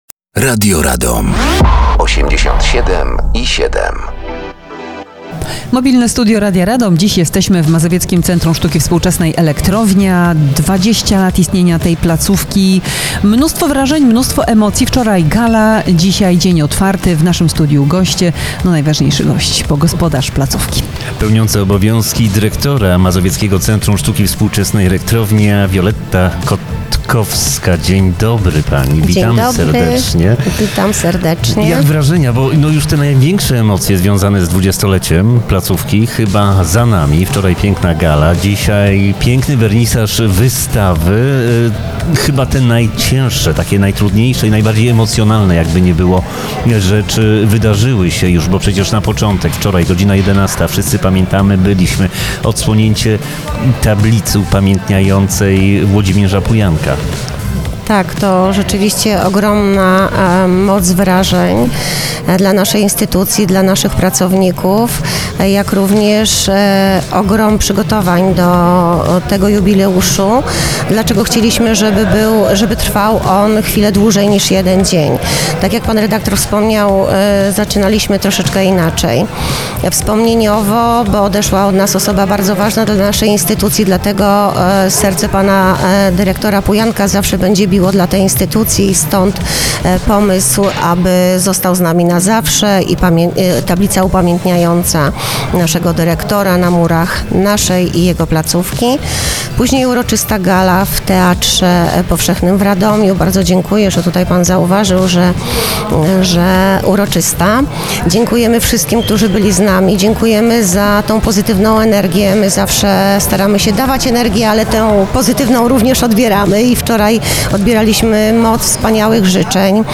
Mobilne Studio Radia Radom dzisiaj w Mazowieckim Centrum Sztuki Współczesnej Elektrownia w Radomiu.